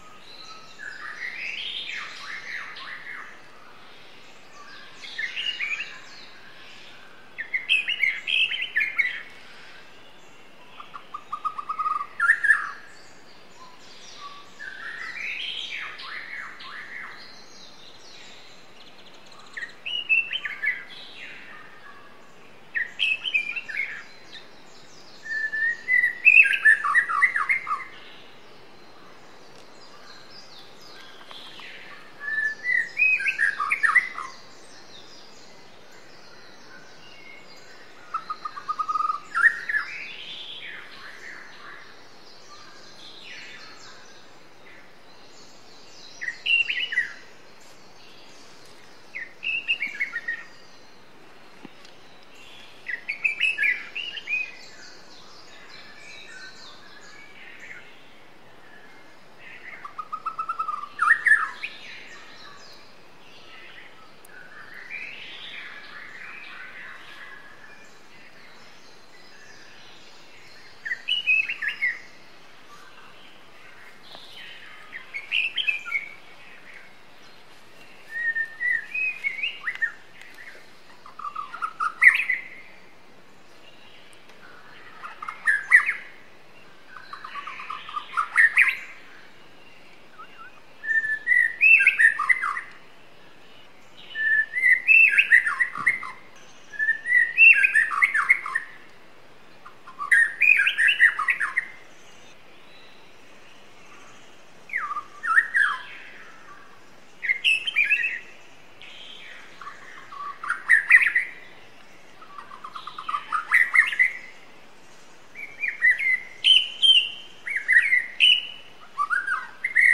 Berikut ini kumpulan audio murai batu alam super gacor yang bisa sobat download.
>> Murai Alam gacor 01